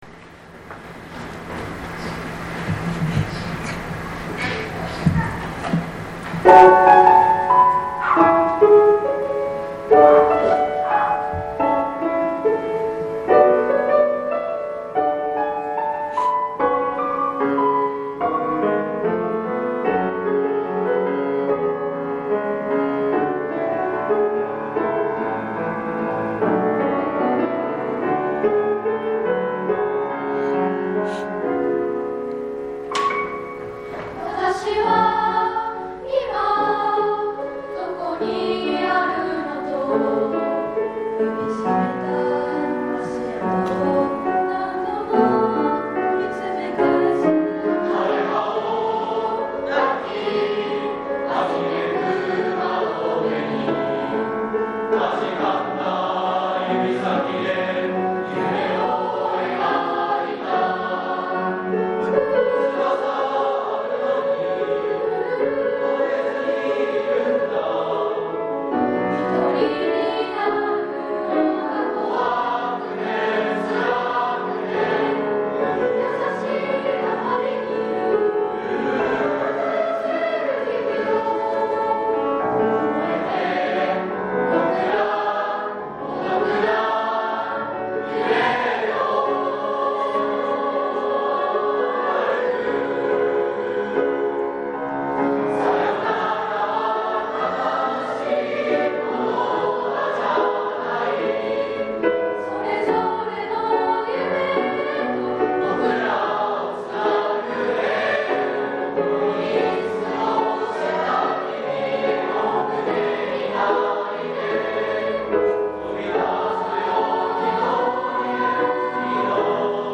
無事、第７７回の卒業式を終えることができました。
式の中の全体合唱の「旅立ちの日に」と卒業生の合唱「YELL」と生徒・教職員で最後に歌った「蛍の光」を聞いてください。